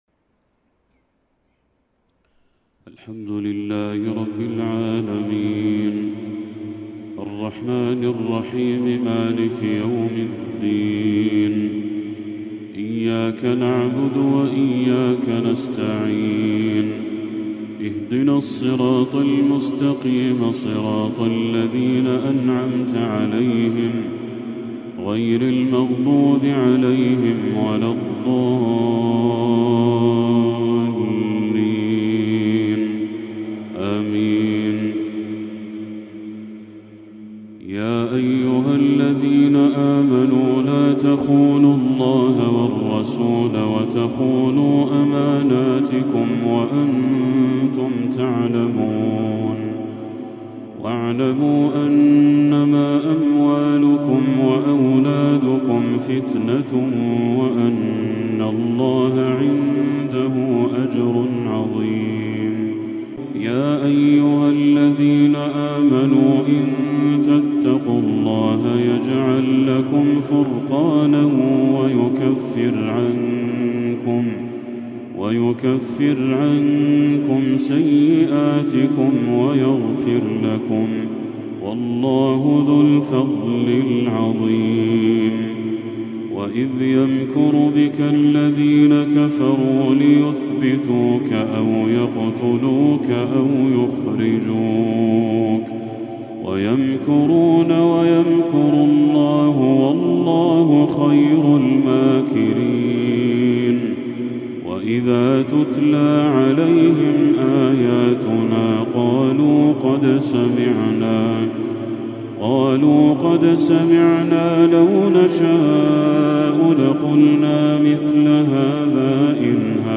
تلاوة تذيب القلب خشوعًا من سورة الأنفال للشيخ بدر التركي | عشاء 13 ربيع الأول 1446هـ > 1446هـ > تلاوات الشيخ بدر التركي > المزيد - تلاوات الحرمين